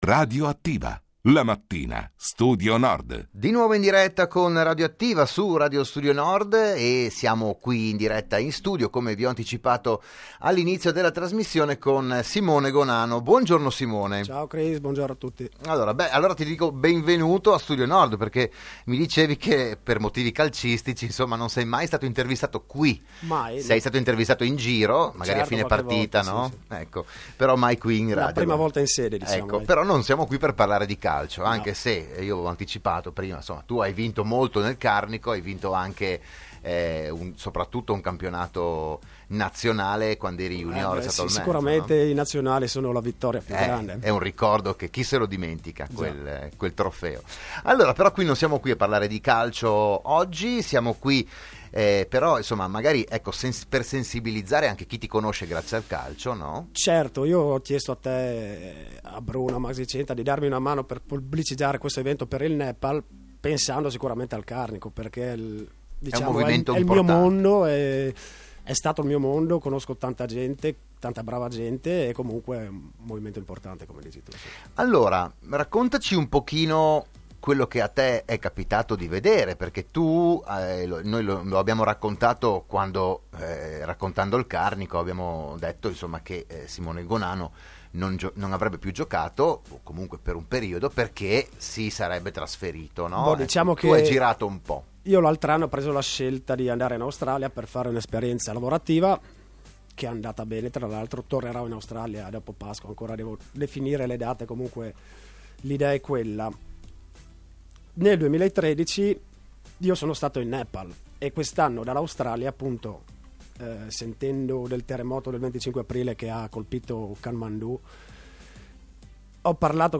Domenica 20 marzo a Enemonzo serata dedicata alla popolazione duramente colpita dal sisma. Il podcast dell'intervista